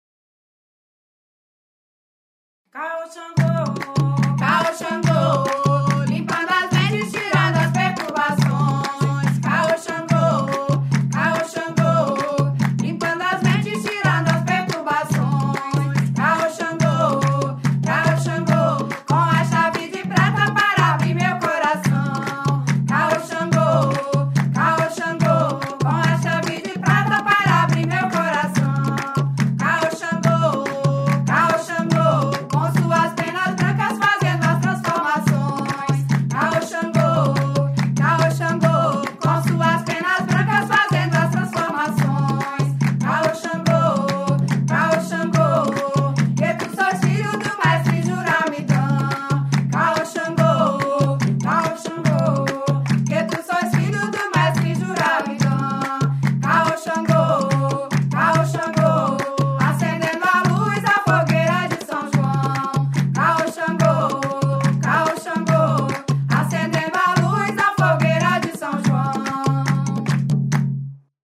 A group of committed Daimistas have come together to make professional recordings of all the pontos and hymns of the Umbandaime 1 workbook, as well as the upcoming Umbandaime 2 workbook.